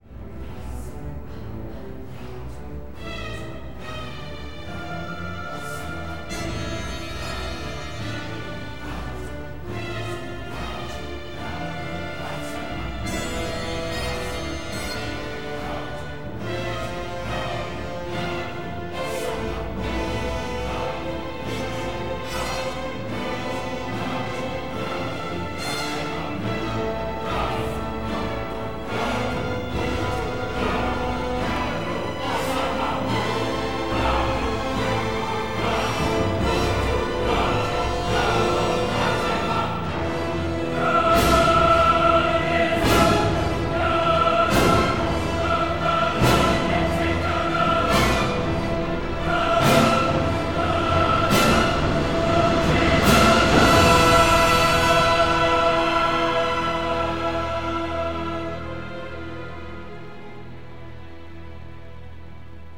Colonna sonora
alla grande orchestra e alla strumentazione tradizionale.